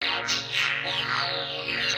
[CC] Talkbox.wav